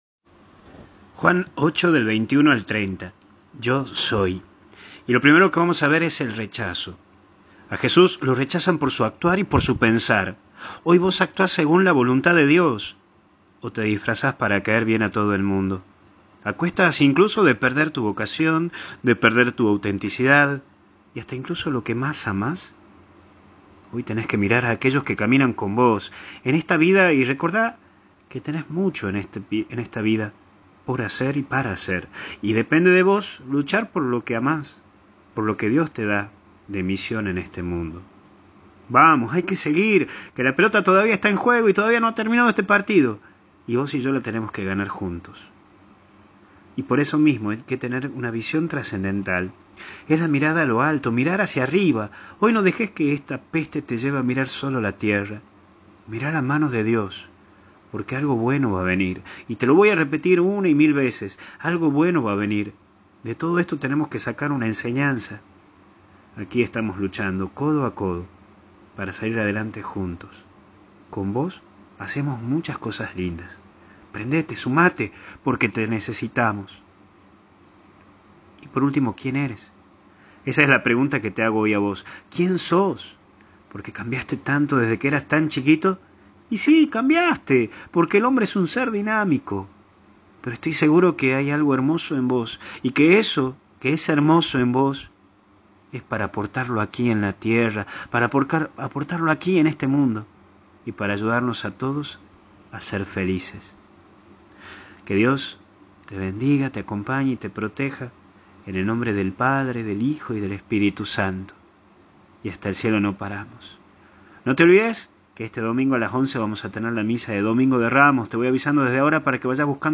Meditación Diaria